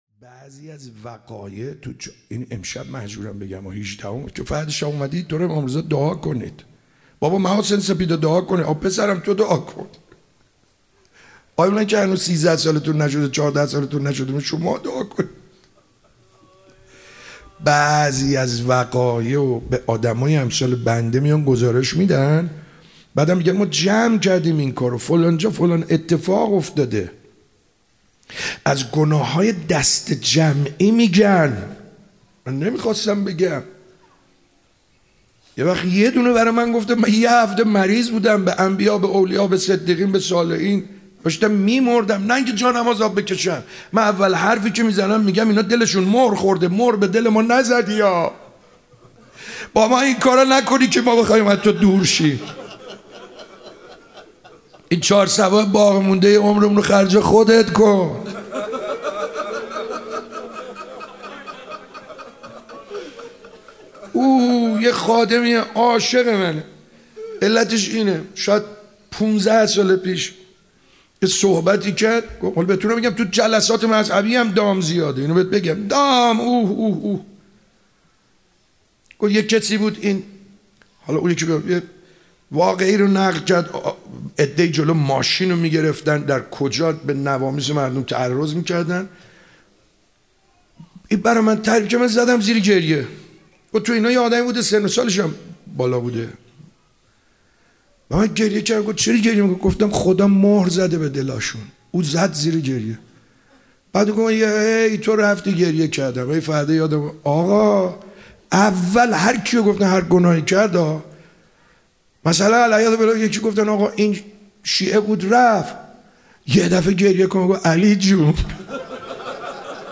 دعا خوانی